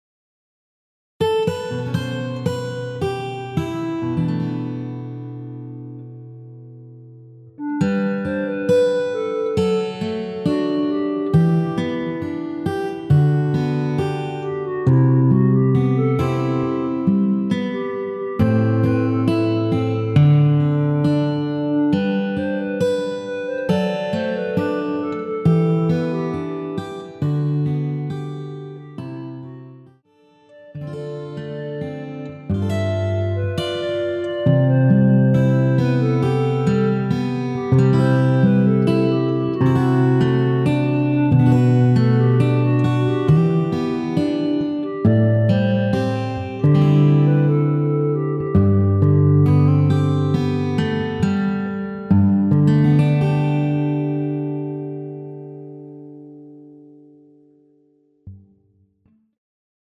1절 “행복해라 내 좋은 친구야~” 까지 하고 엔딩을 만들었습니다.(미리듣기 확인)
원키에서(+3)올린 1절로 편곡한 멜로디 포함된 MR입니다.
멜로디 MR이라고 합니다.
앞부분30초, 뒷부분30초씩 편집해서 올려 드리고 있습니다.
중간에 음이 끈어지고 다시 나오는 이유는
축가 MR